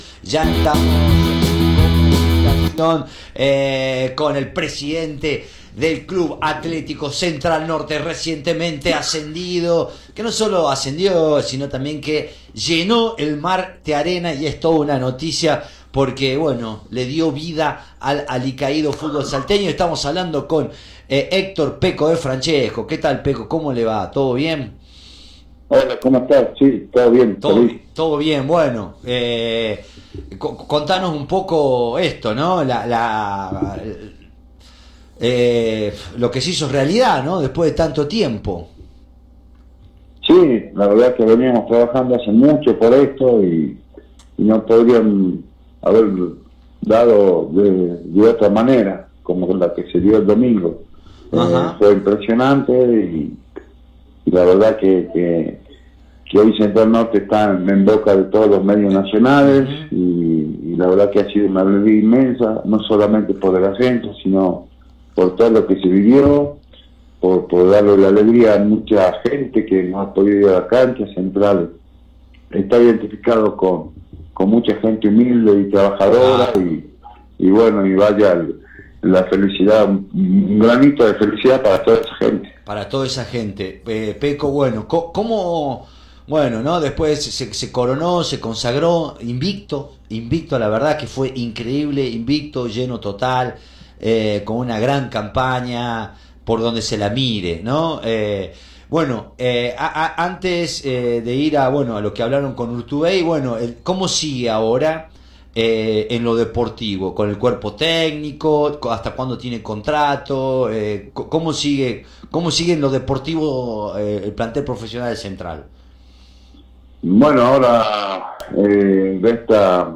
en diálogo con Radio Dinamo se refirió a lo que viene en la entidad Azabache después del esperado ascenso.